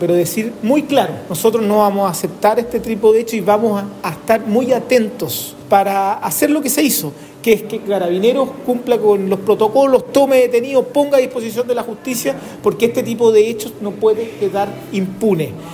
Por otra parte, delegado Presidencial en La Araucanía, José Montalva, manifestó su enérgico rechazo al hecho